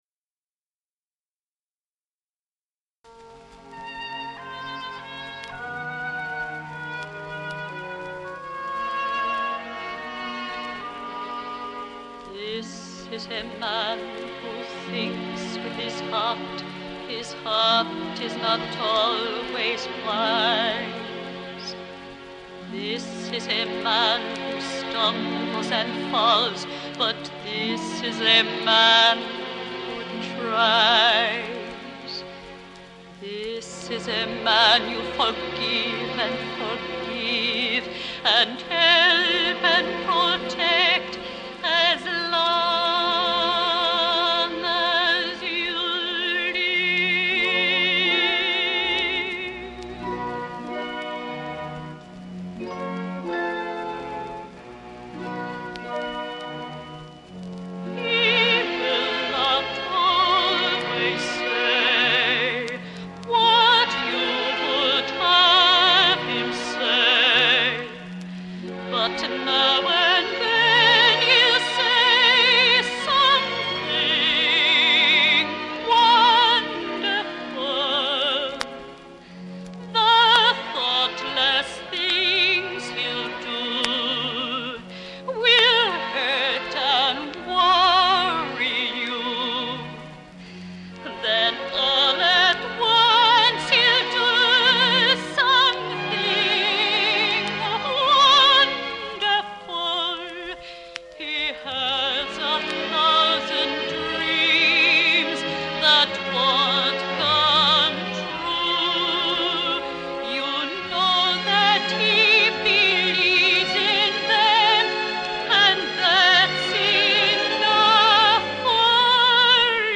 HIGH FIDELITY STEREOPHONIC RECORDING